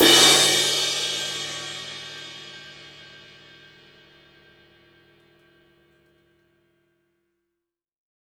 Crash OS 01.wav